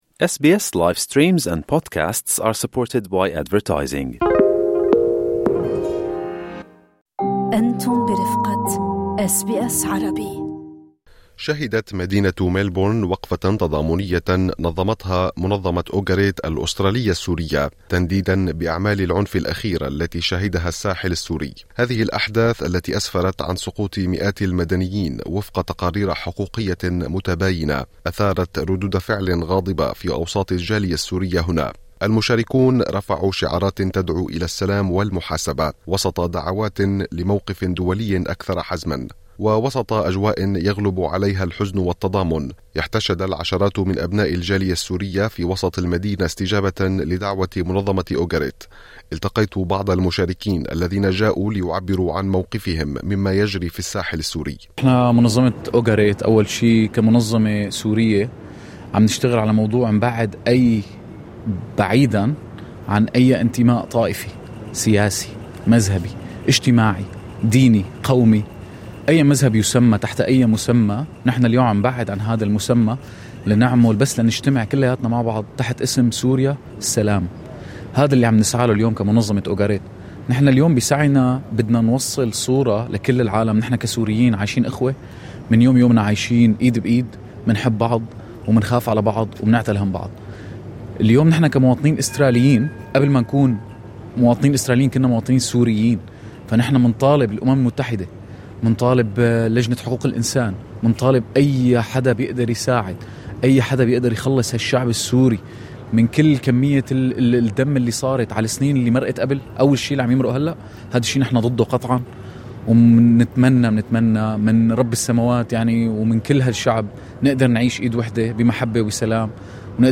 وقفة تضامنية في ملبورن تنديداً بالعنف في الساحل السوري